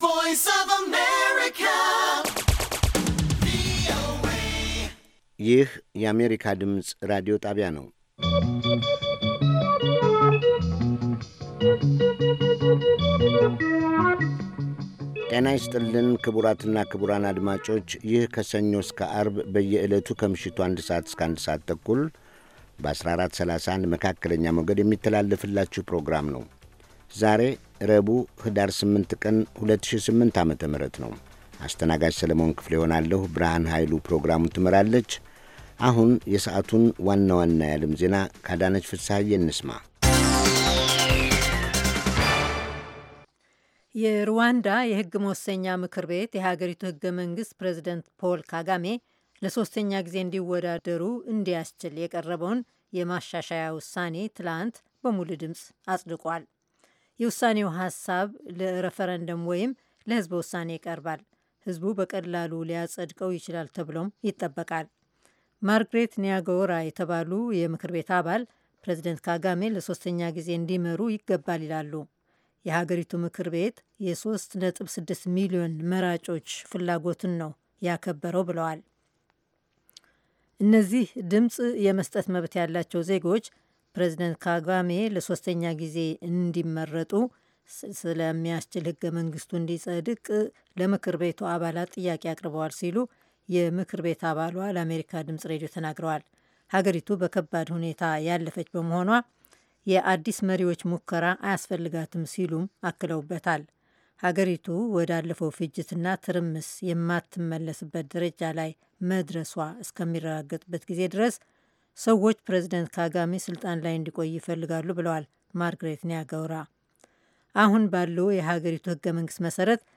ከምሽቱ አንድ ሰዓት የአማርኛ ዜና